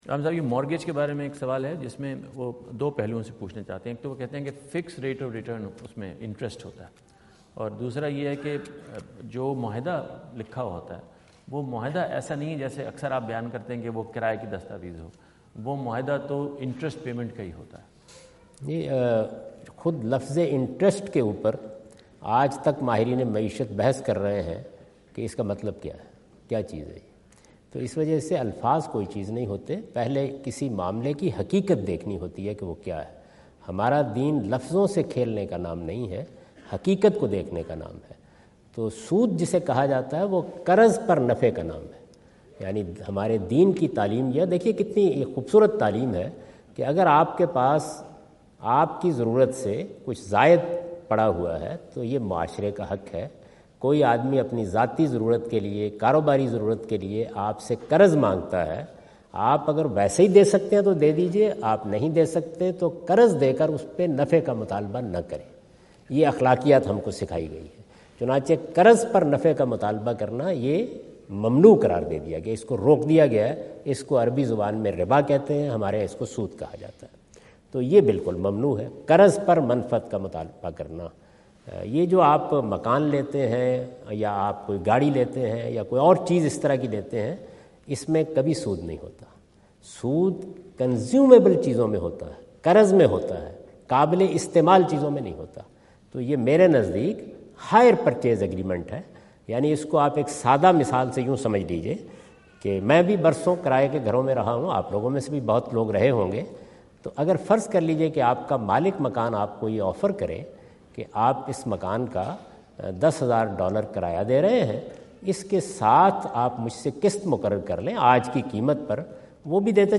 Category: English Subtitled / Questions_Answers /
Javed Ahmad Ghamidi answer the question about "Hire-Purchase Agreement and Interest" asked at North Brunswick High School, New Jersey on September 29,2017.
جاوید احمد غامدی اپنے دورہ امریکہ 2017 کے دوران نیوجرسی میں "اقساط میں کرایہ و قیمت کی ادائیگی اور سود کا مسئلہ" سے متعلق ایک سوال کا جواب دے رہے ہیں۔